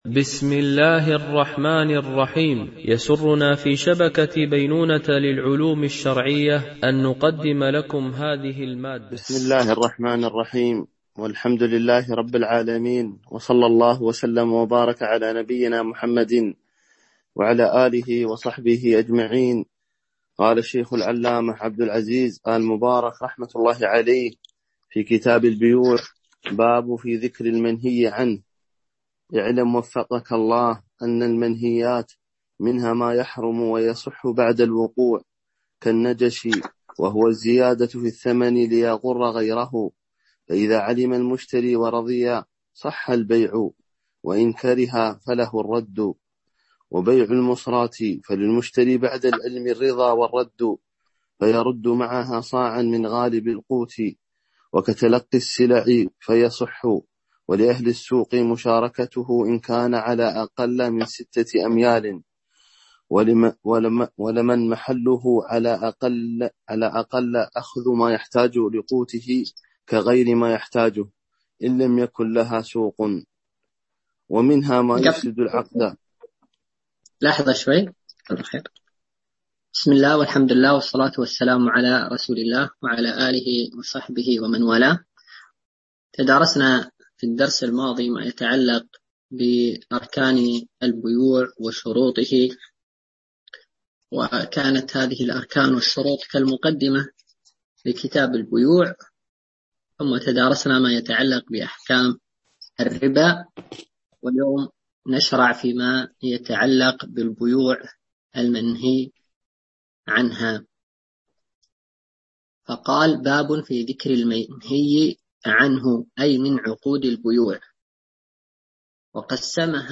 شرح الفقه المالكي ( تدريب السالك إلى أقرب المسالك) - الدرس 43 ( كتاب البيوع )